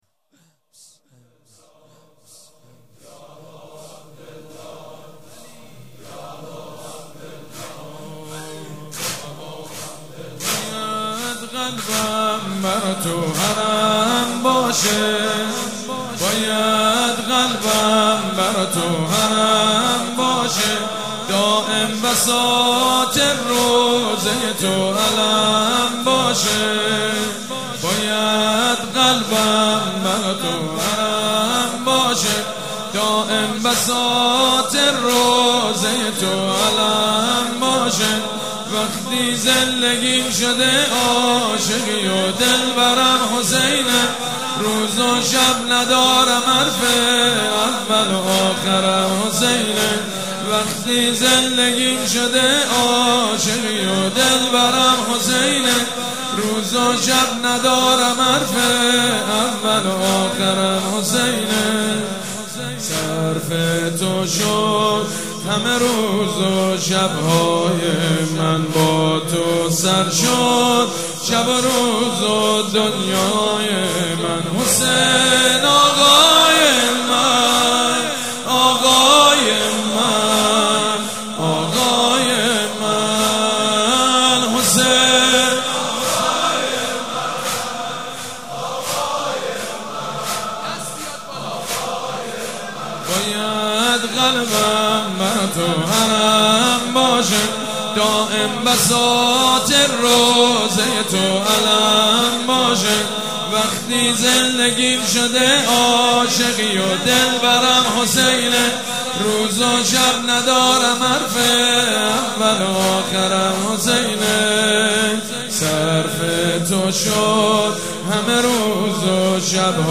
شب اول محرم الحرام
مداح
حاج سید مجید بنی فاطمه
مراسم عزاداری شب اول